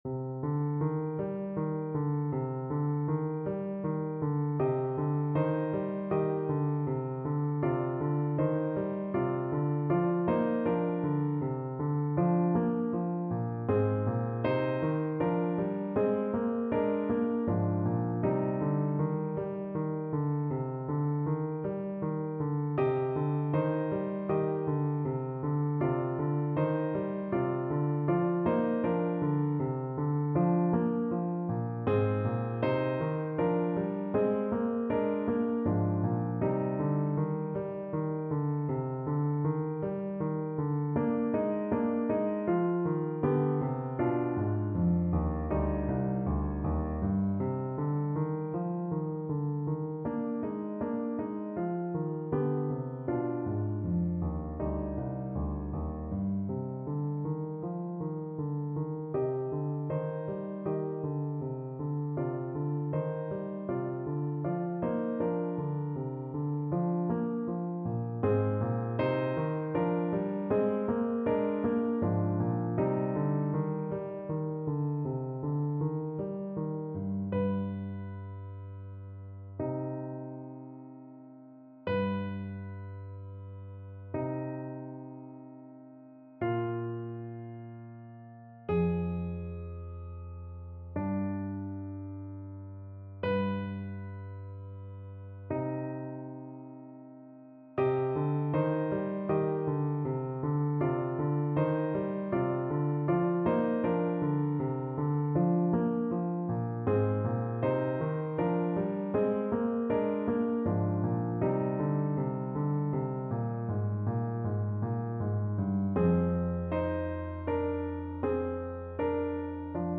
Clarinet
3/4 (View more 3/4 Music)
C minor (Sounding Pitch) D minor (Clarinet in Bb) (View more C minor Music for Clarinet )
Andante (one in a bar) = 120
Classical (View more Classical Clarinet Music)
grandosspanishdancesno2_CL_kar3.mp3